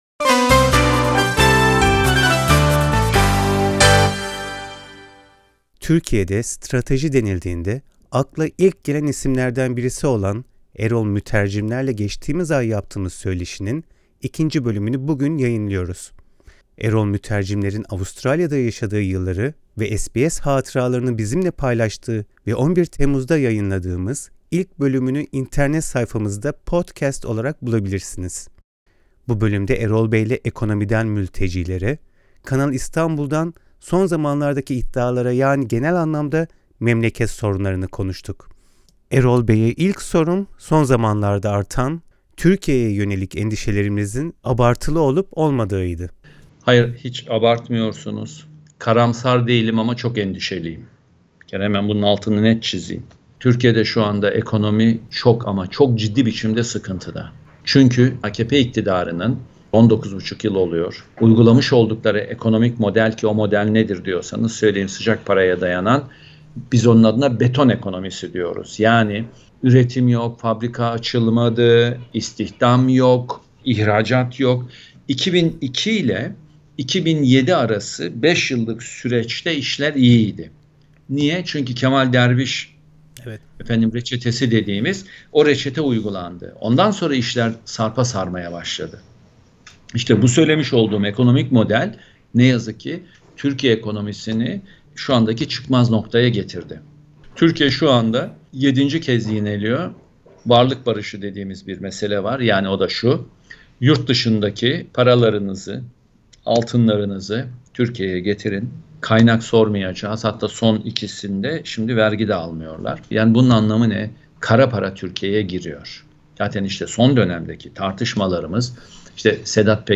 Erol Mütercimler’le geçtiğimiz ay yaptığımız söyleşinin, genel anlamda memleket sorunlarını konuştuğumuz ikinci bölümünü bugün yayınlıyoruz.